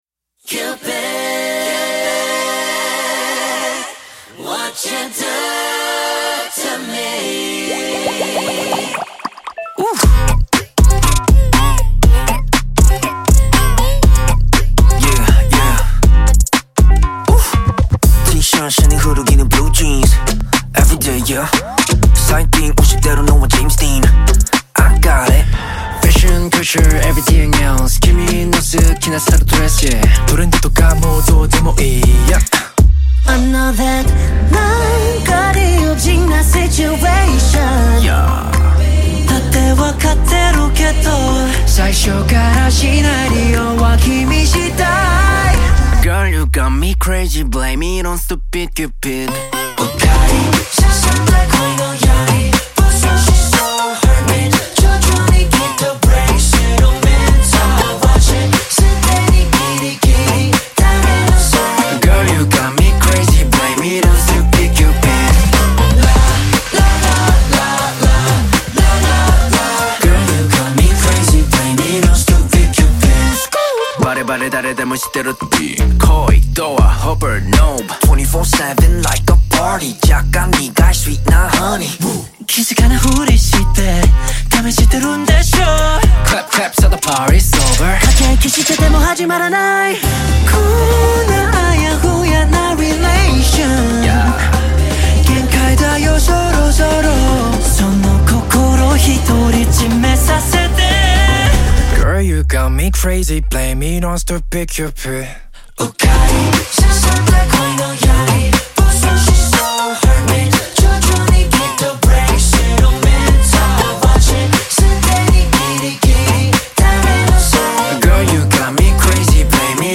Genre – J-POP